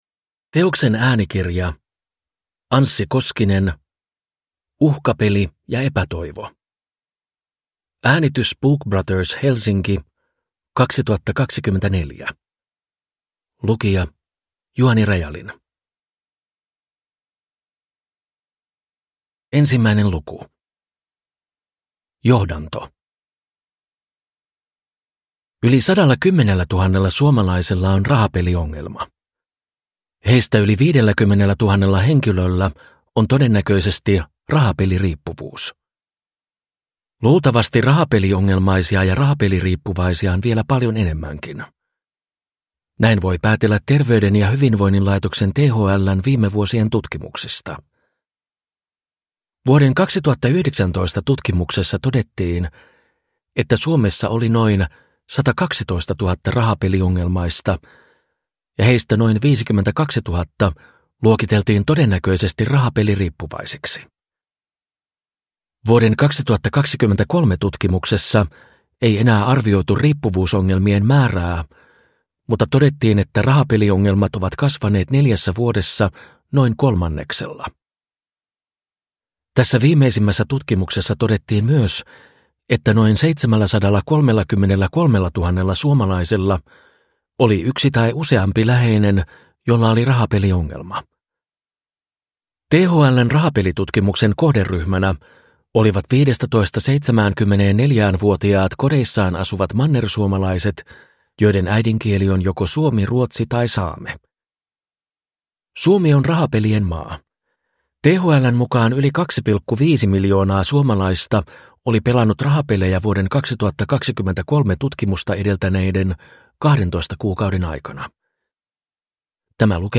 Uhkapeli ja epätoivo – Ljudbok